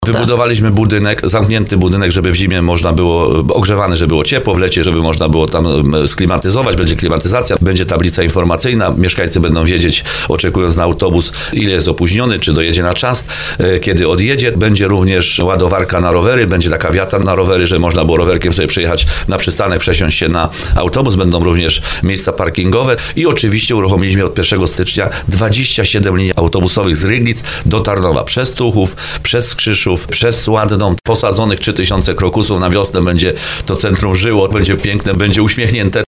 Jak mówi burmistrz Ryglic Paweł Augustyn, obiekt będzie klimatyzowany i ogrzewany, wyposażony w elektroniczną tablicę informacyjną oraz toalety.